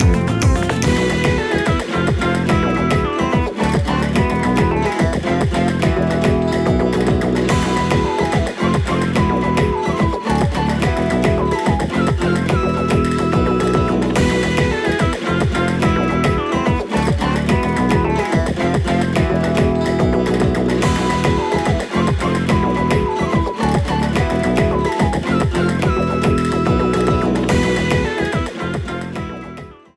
A boss battle theme
Ripped from game
clipped to 30 seconds and applied fade-out